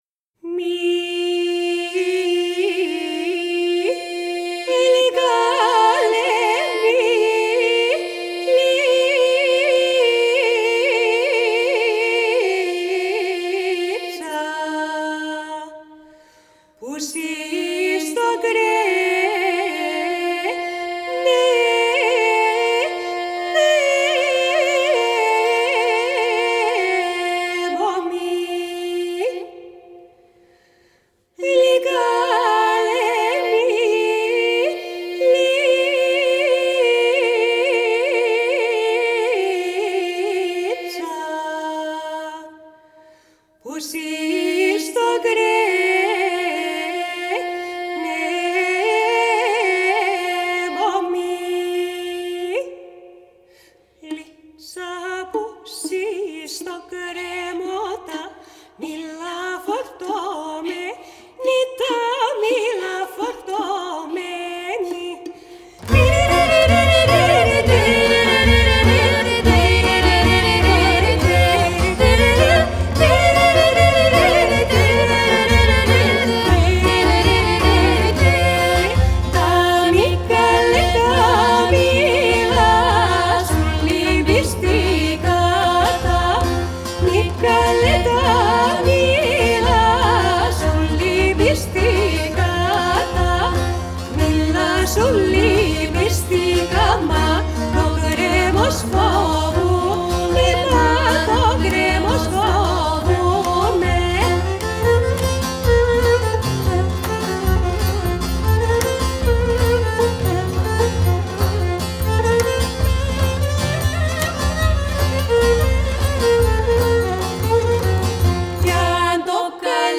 Genre: World, Balkan Music